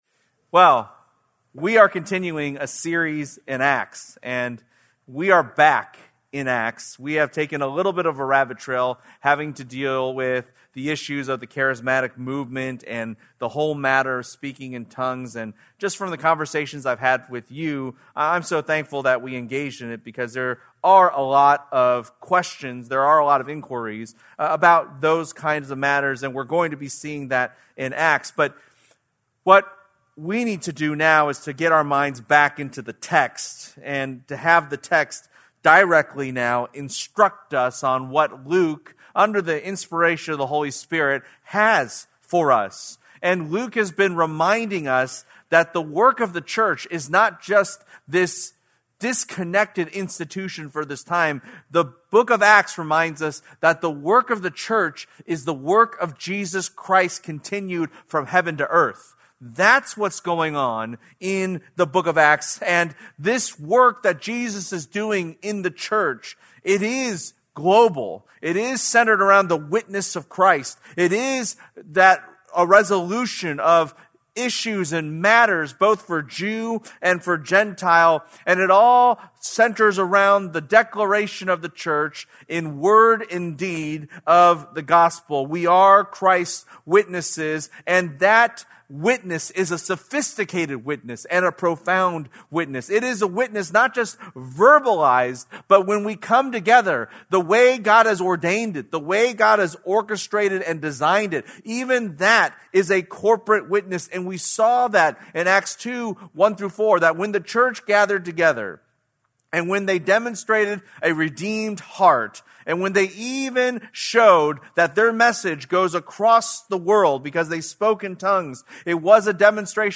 February 22, 2026 - Sermon | Crossroads | Grace Community Church